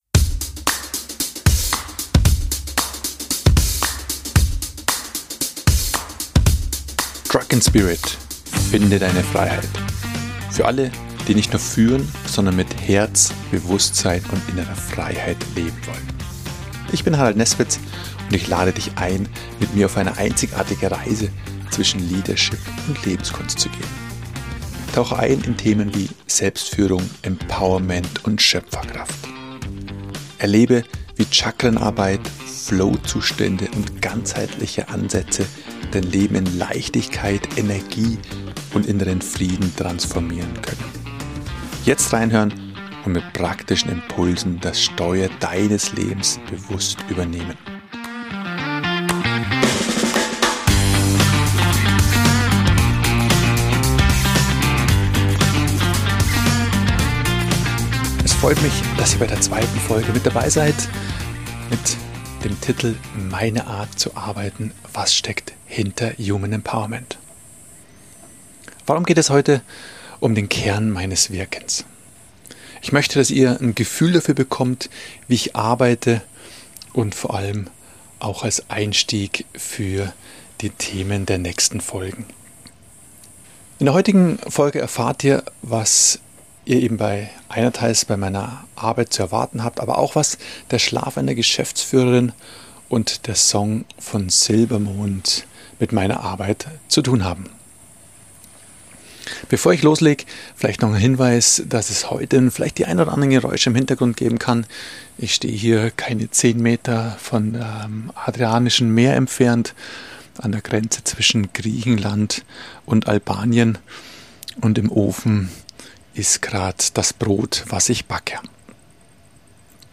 am Meer in Griechenland und spricht über Führung, Schlafstörungen und Chakrenarbeit? + Und was hat der Song „Leichtes Gepäck“ von Silbermond mit Coaching zu tun?